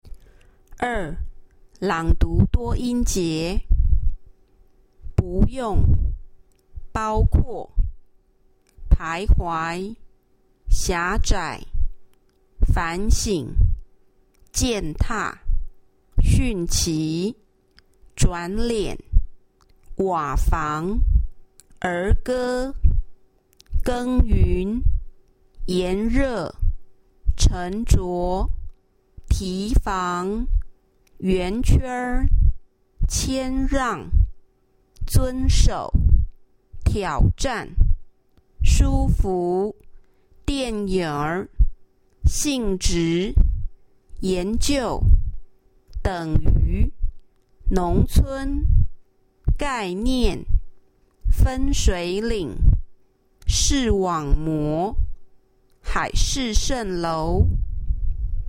Tasks 1&2 Word Reading
Taiwan Sample: